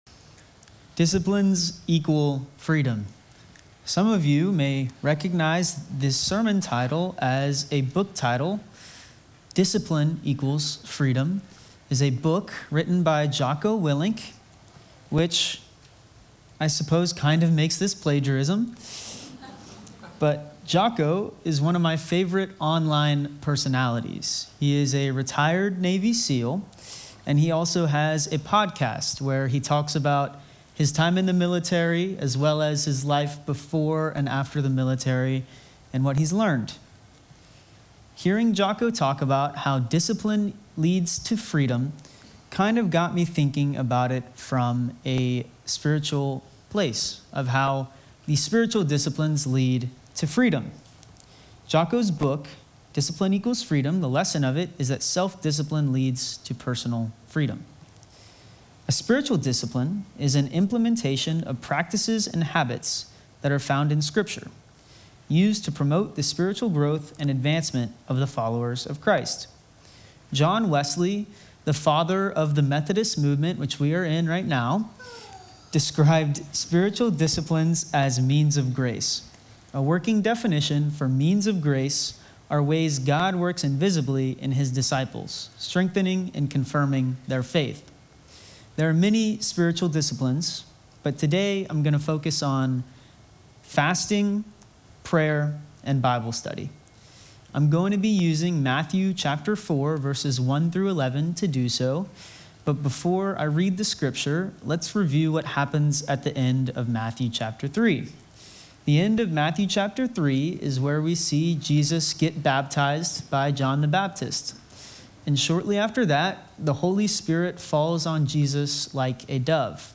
Sermon text: Matthew 4:1-11